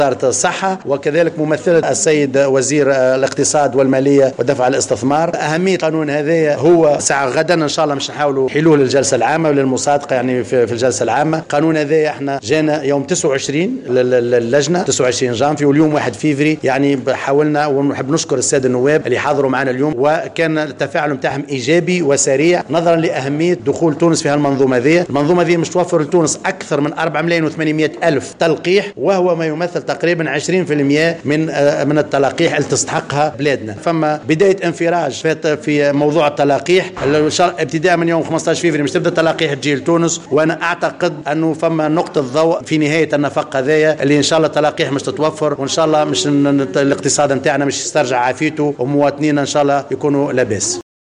اكد رئيس لجنة الصحة والشؤون الاجتماعية بمجلس نواب الشعب، العياشي زمال، في تصريح لـ "الجوهرة أف أم" اليوم الاثنين، ان التلقيح ضد كورونا سيكون متوفرا في تونس بداية من 15 فيفري 2021.